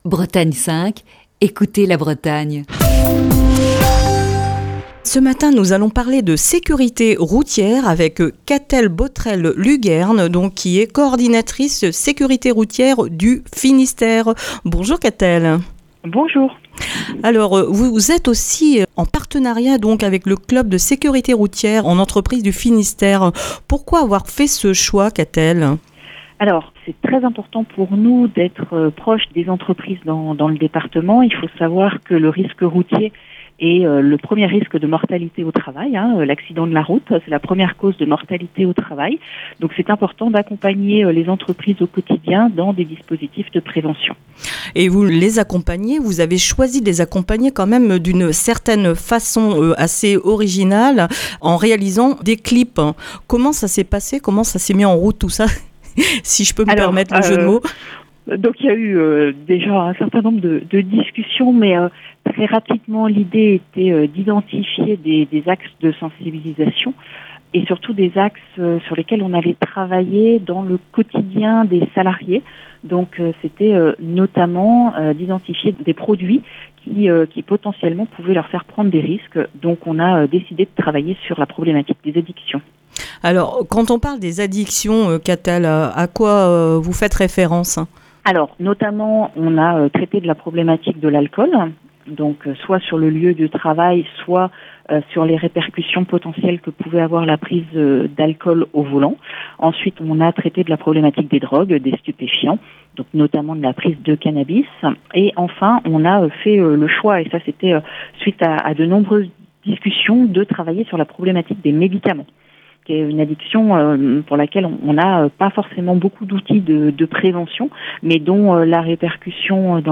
est au téléphone